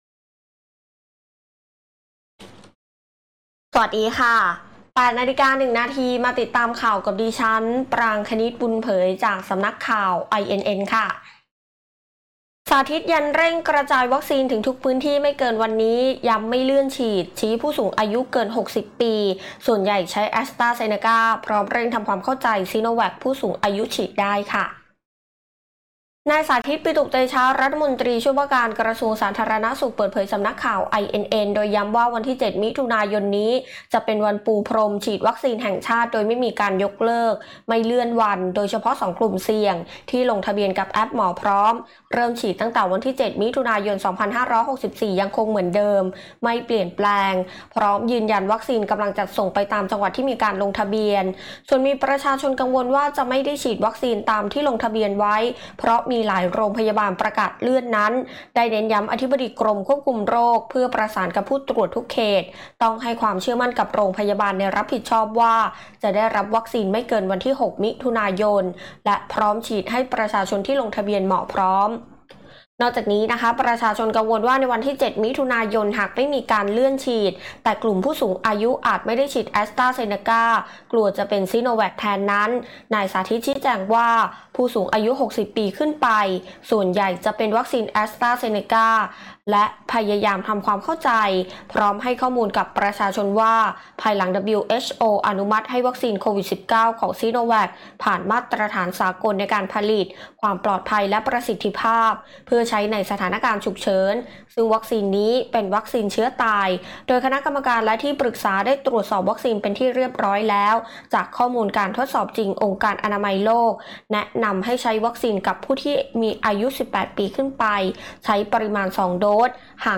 คลิปข่าวต้นชั่วโมง
ข่าวต้นชั่วโมง 08.00 น.